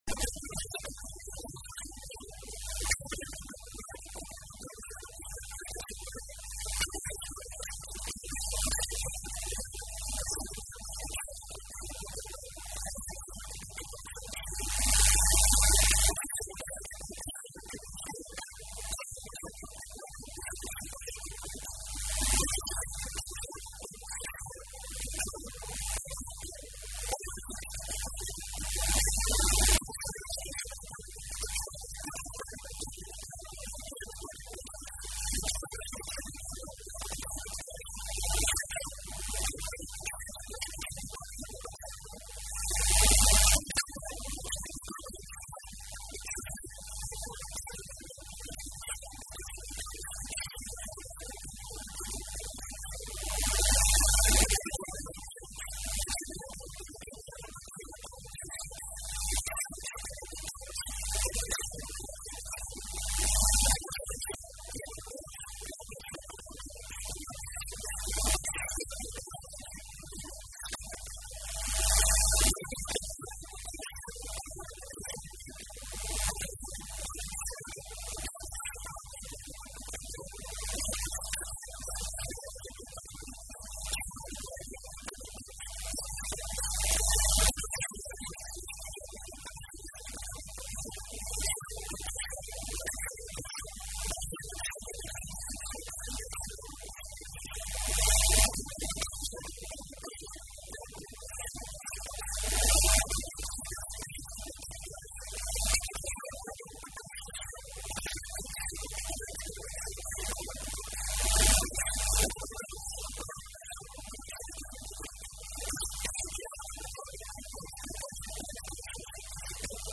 O Secretário Regional dos Recursos Naturais afirmou hoje, na Assembleia Legislativa, na Horta, que a qualidade do ambiente nos Açores constitui um “importante propulsor de investimento” no arquipélago.